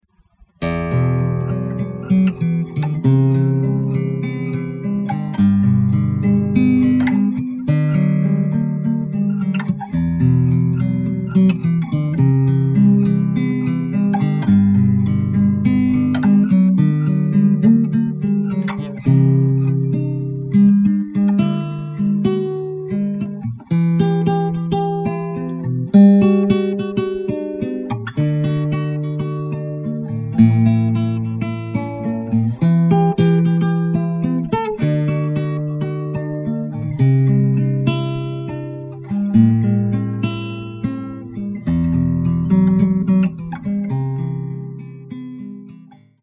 ми-минор